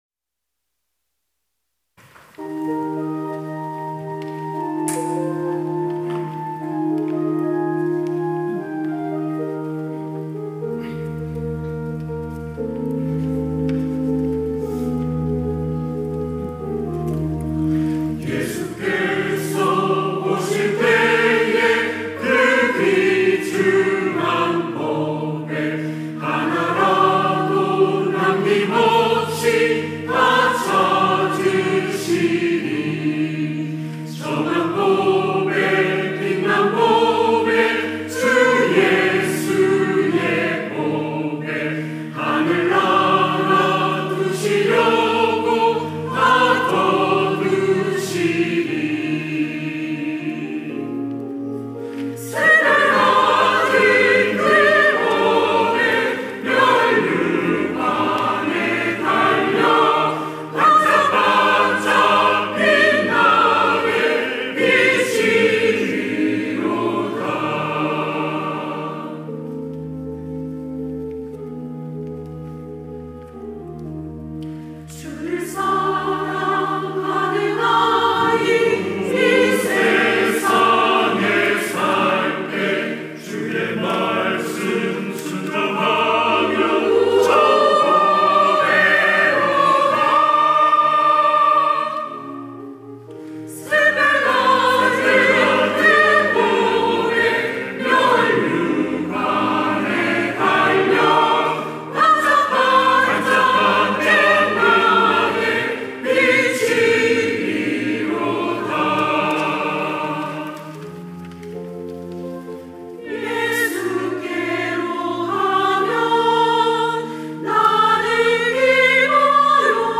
천안중앙교회
찬양대 가브리엘